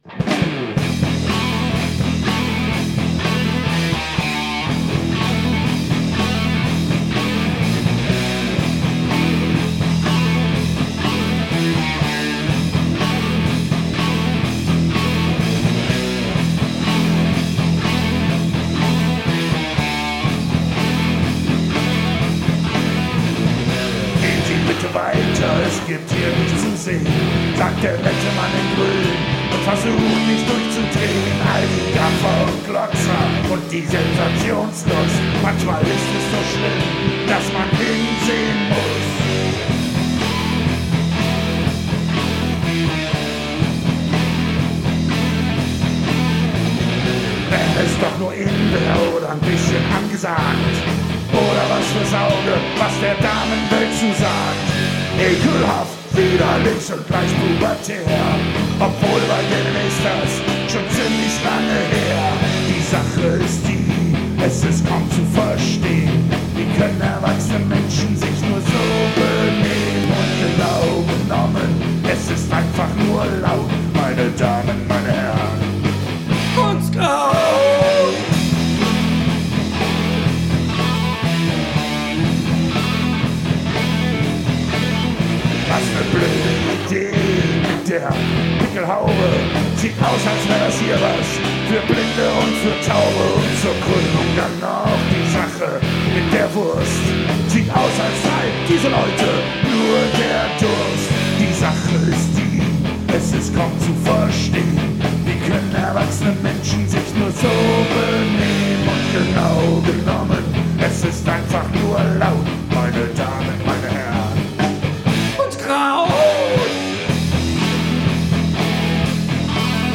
Rockgeschichte
Obwohl noch etwas roh, hat unsere Hörprobe Charme